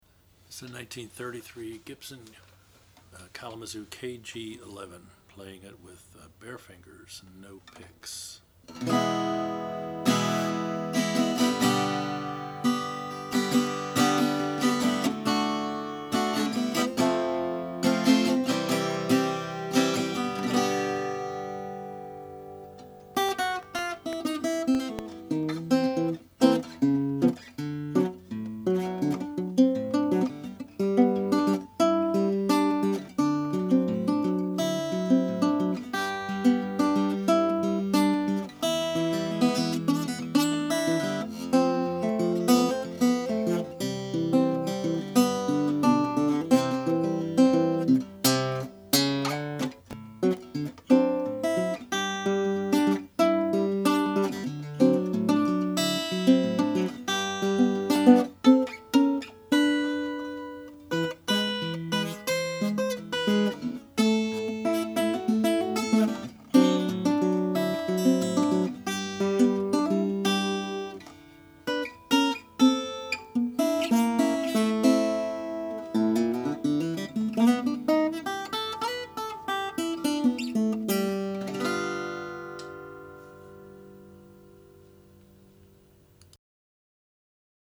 1933 Kalamazoo KG11 Sunburst
The top is solid spruce, stained dark, with a small sunburst, firestripe pickguard and bound in white celluloid.
The top is ladder braced.